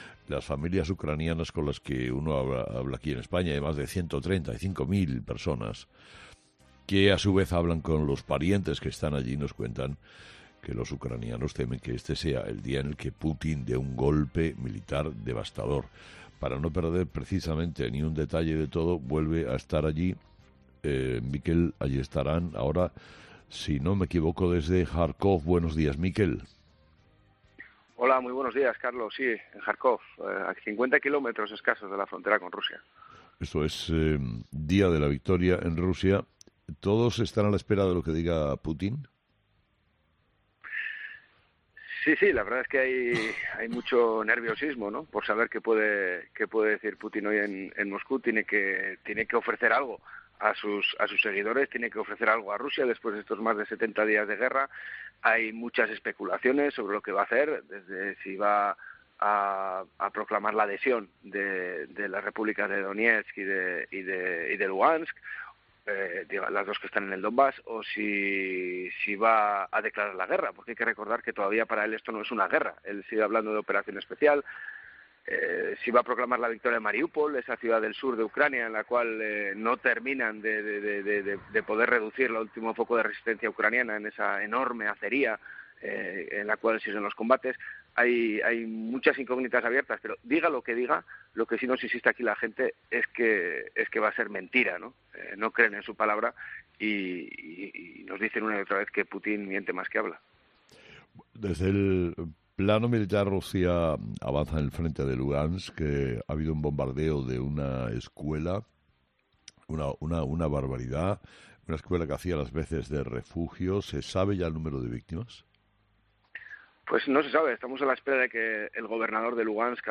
Últimas Noticias/Entrevistas